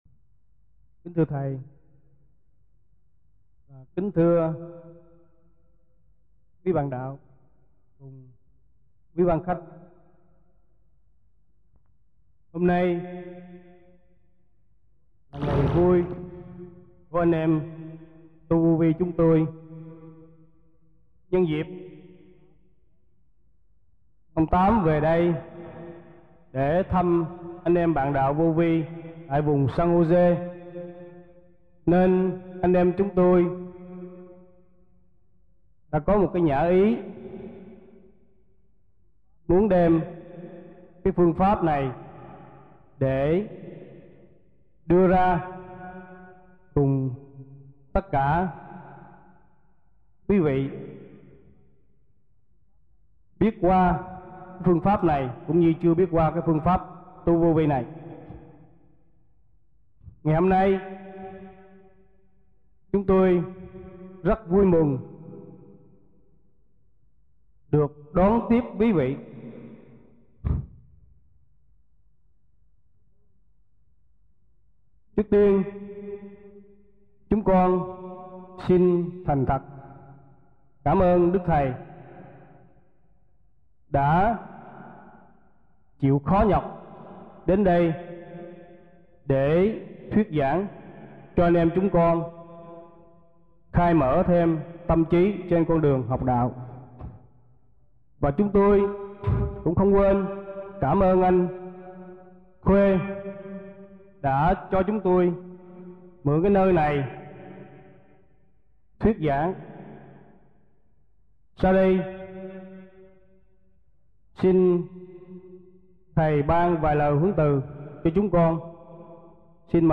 1994 Đàm Đạo
1994-08-03 - THIỀN VIỆN HAI KHÔNG - LUẬN ĐẠO 1